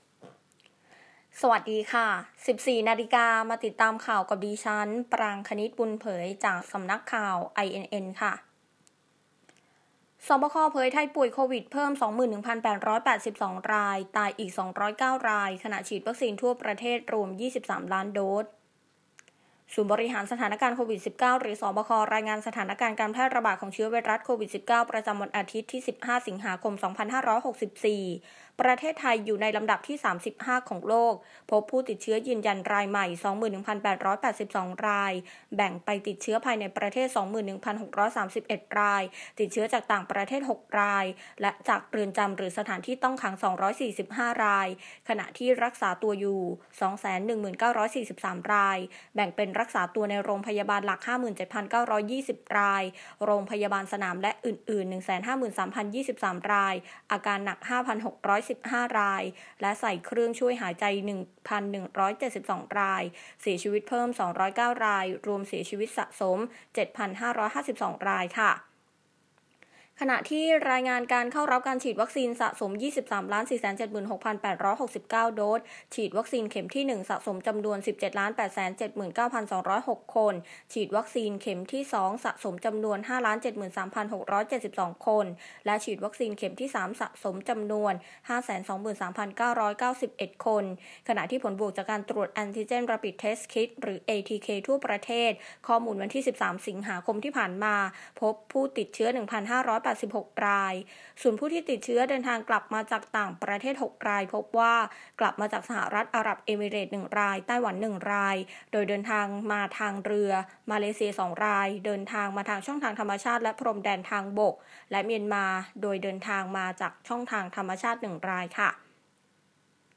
บรรยากาศจุดฉีดวัคซีนที่เซ็นทรัลเวิลด์ ประชาชนทยอยเดินทางเข้ารับวัคซีนอย่างต่อเนื่อง โดยในวันนี้เป็นการฉีดวัคซีนของผู้ลงทะเบียนของรพ.จุฬาฯ และในช่วงนี้หยุดฉีดวัคซีนสำหรับผู้ลงทะเบียนไทยร่วมใจไปก่อน